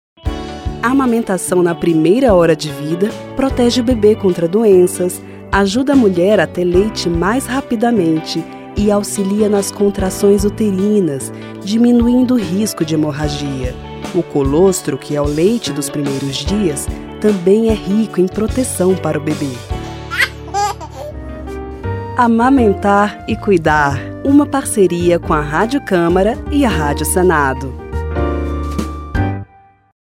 Por isso, a Rádio Câmara, em parceria com a Rádio Senado, lança a campanha “Amamentar e cuidar”. São cinco spots de 30 segundos cada.